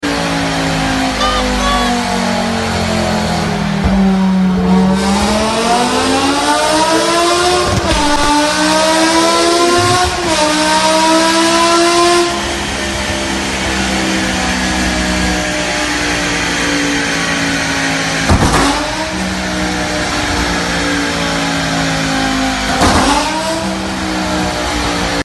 M6 e63 v10 pure sound sound effects free download